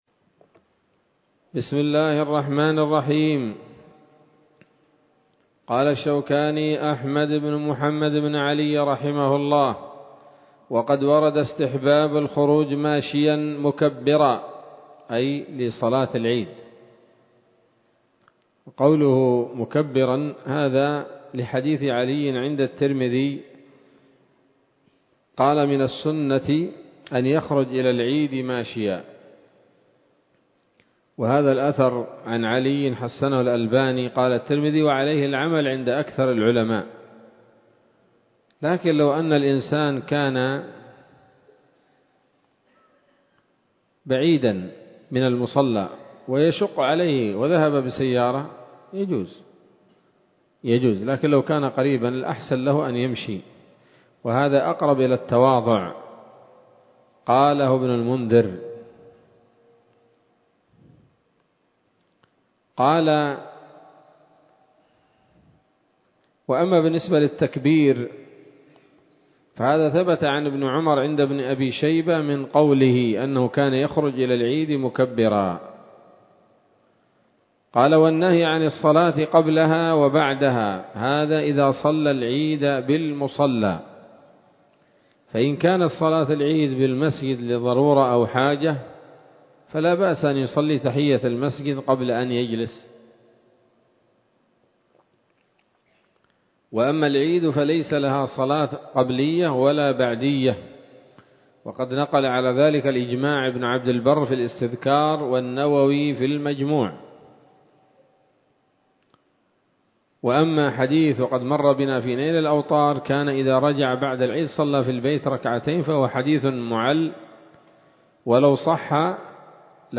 الدرس التاسع والأربعون من كتاب الصلاة من السموط الذهبية الحاوية للدرر البهية